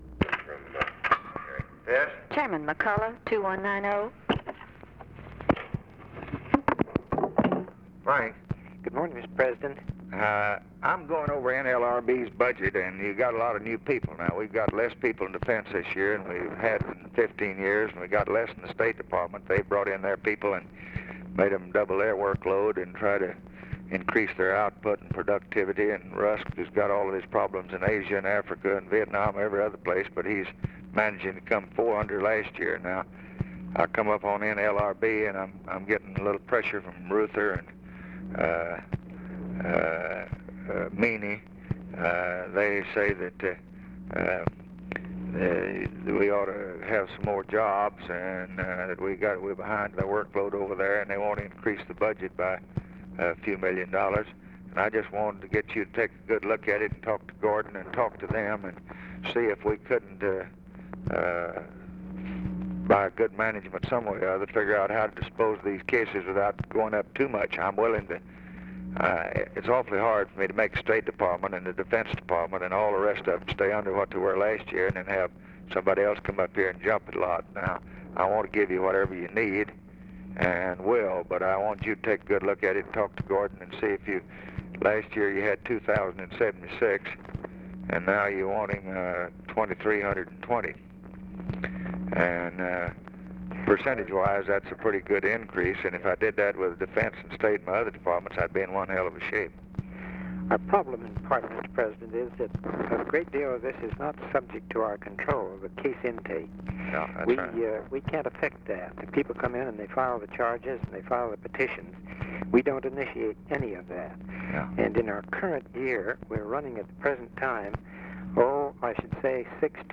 Conversation with FRANK MCCULLOCH, December 19, 1963
Secret White House Tapes